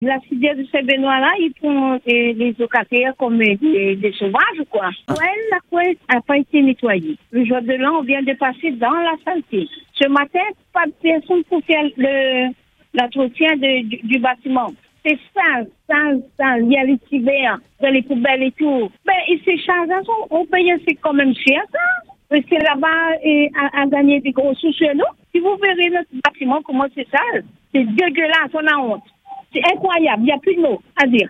Podcast – Saint-Benoît : « des couloirs insalubres à la résidence Antiope », une locataire excédée – Freedom